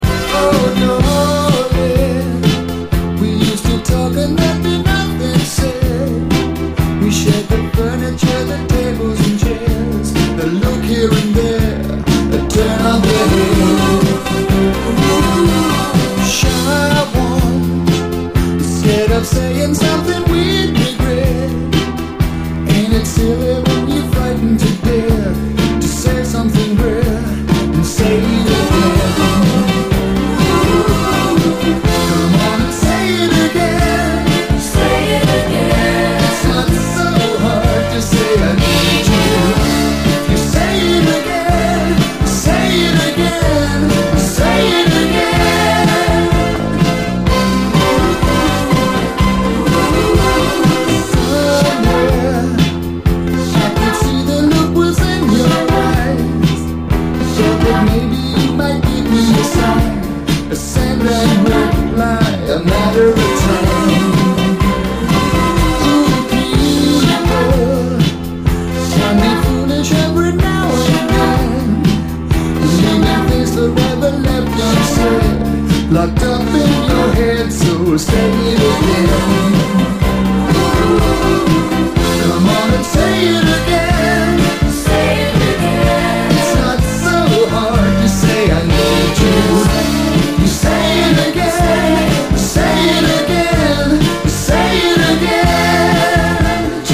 80's～ ROCK, ROCK, 7INCH
ネオアコ〜ギター・ポップのルーツ的80’Sポップ・グループ！